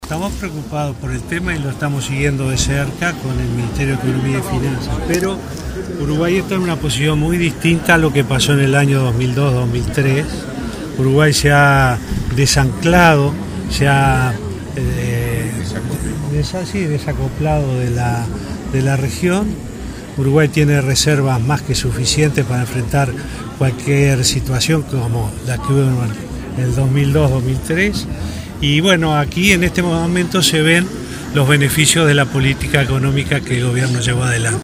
Uruguay está en una situación financiera muy distinta a la de 2002 porque implementó una política financiera que logró desacoplarse de la región y dispone de reservas suficientes para paliar un eventual impacto de Argentina en el país, afirmó el presidente Tabaré Vázquez, previo al Consejo de Ministros de abierto de Florida.